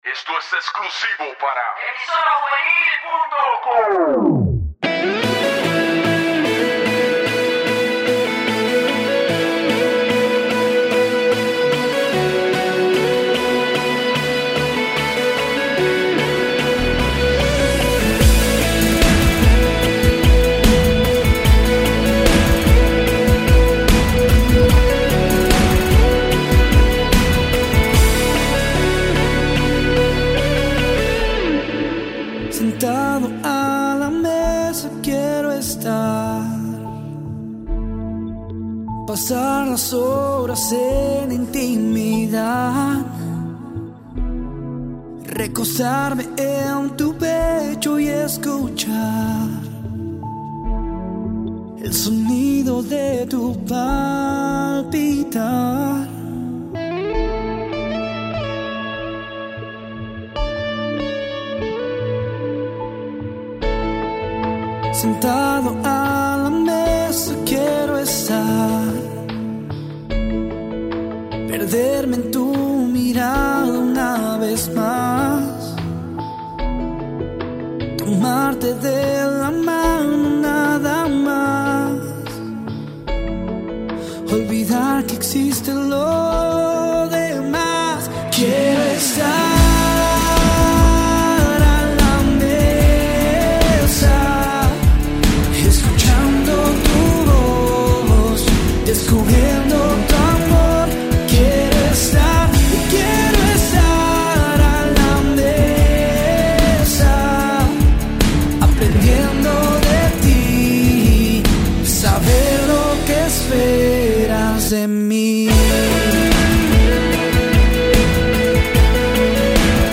Musica Cristiana